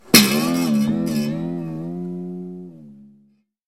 Звуки струн
Звук второй вариант с эффектом порванной струны